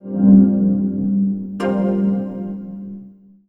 Index of /90_sSampleCDs/USB Soundscan vol.51 - House Side Of 2 Step [AKAI] 1CD/Partition D/02-FX LOOPS